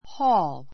hɔ́ːl ホ ー る